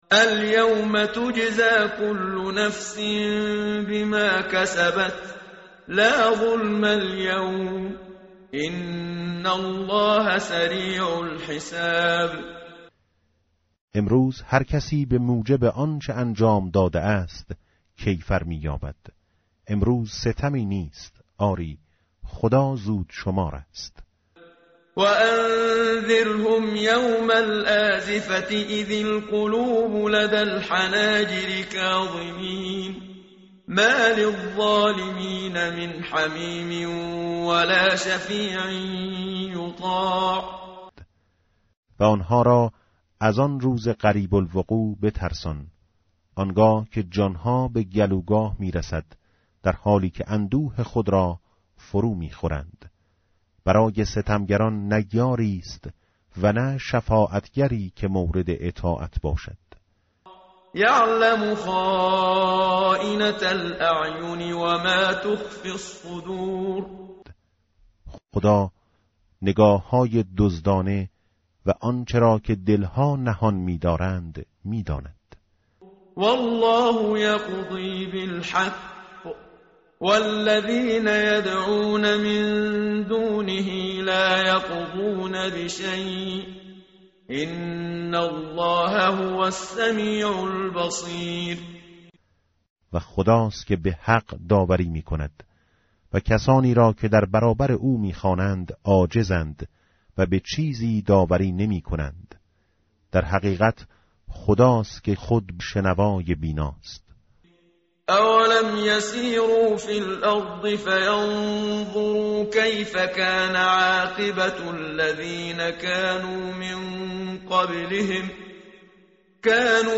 متن قرآن همراه باتلاوت قرآن و ترجمه
tartil_menshavi va tarjome_Page_469.mp3